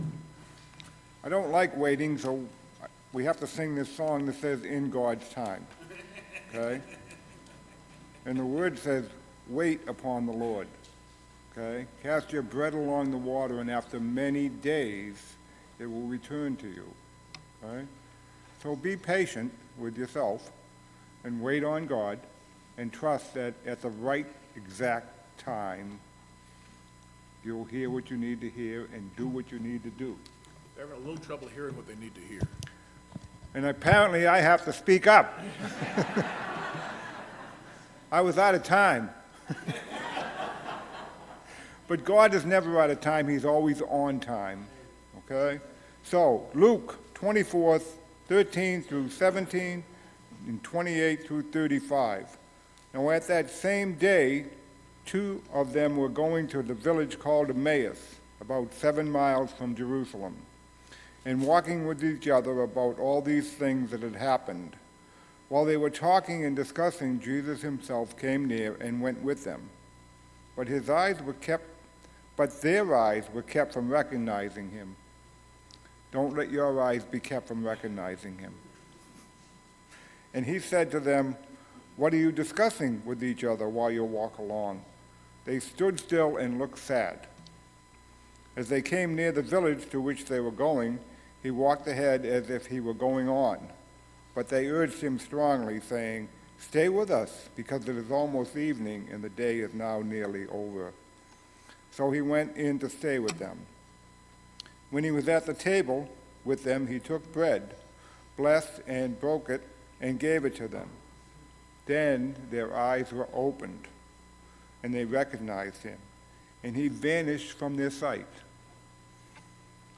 Scripture-Reading-and-Sermon-Oct.-8-2023.mp3